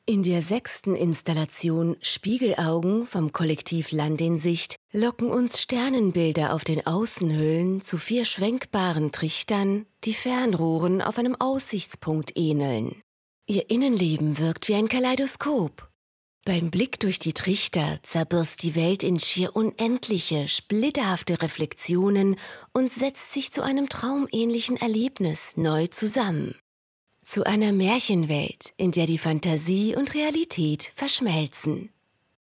Zauberpark 2025 - Audiodeskription Lichtkunst